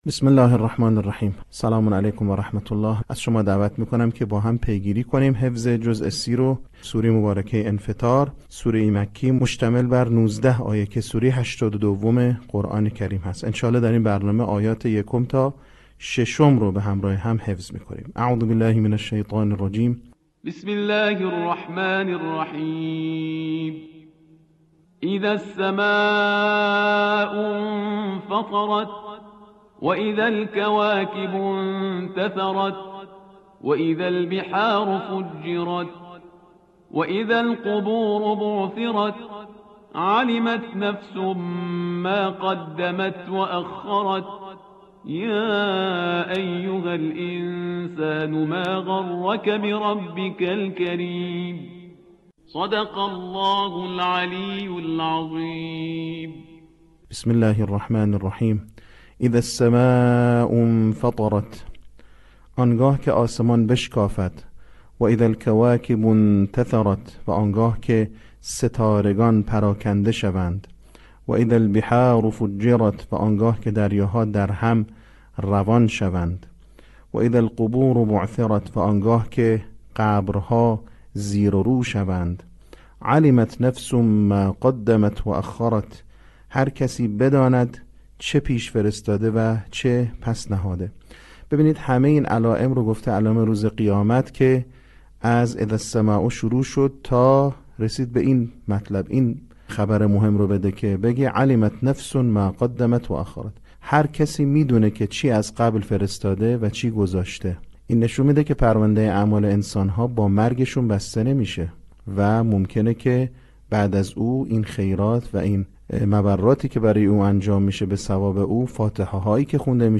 صوت | آموزش حفظ سوره انفطار